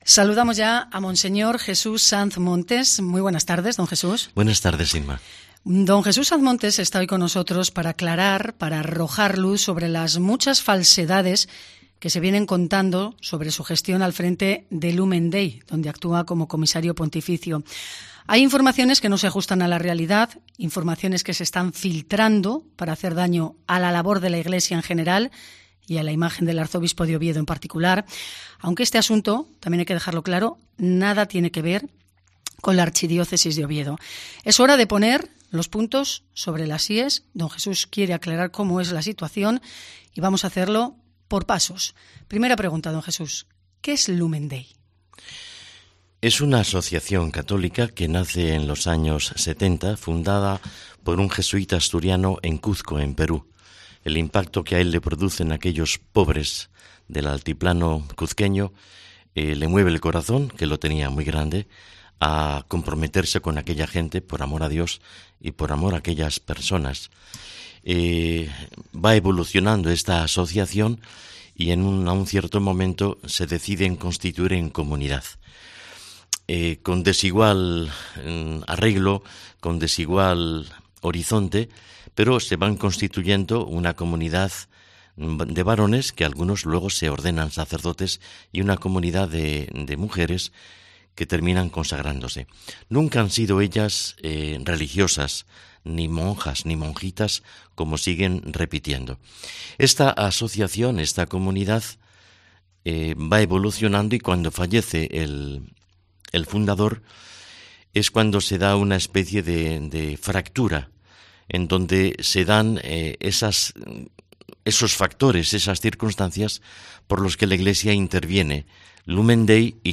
Entrevista al Arzobispo de Oviedo, don Jesús Sanz Montes